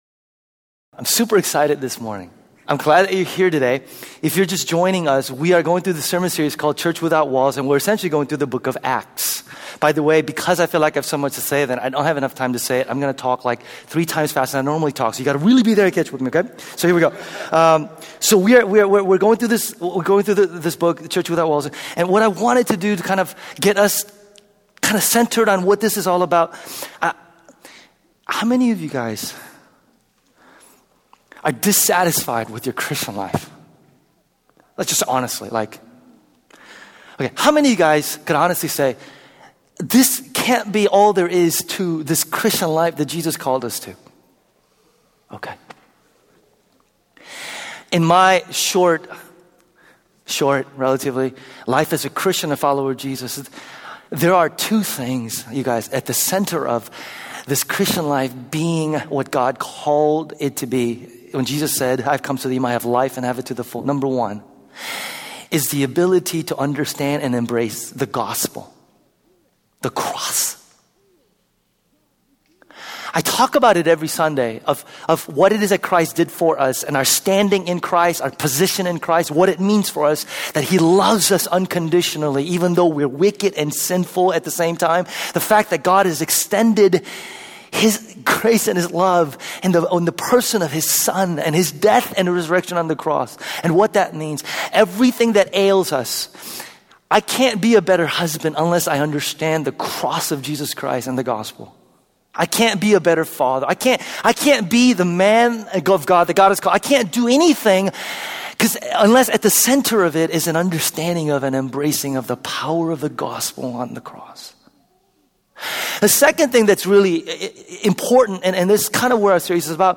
Church Without Walls week 3: Preparing for the Mission (part 1). sermon text: acts 1:1-15